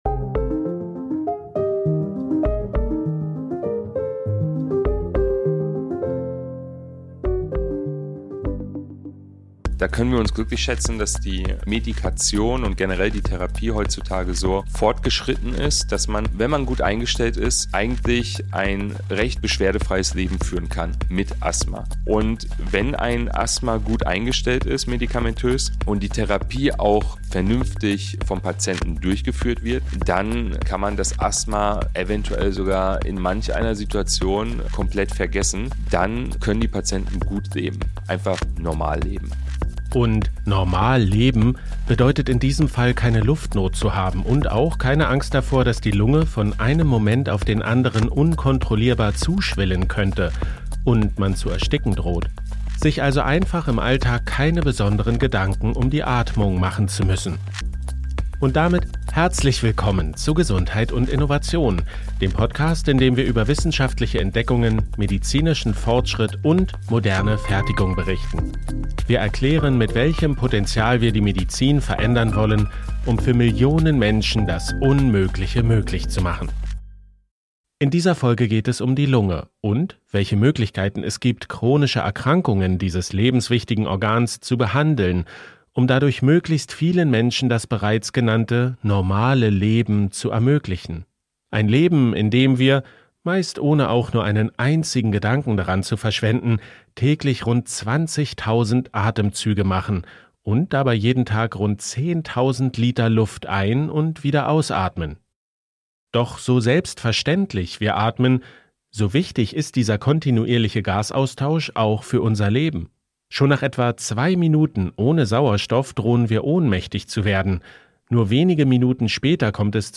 Wir sprechen mit 2 medizinischen Experten über COPD und Asthma.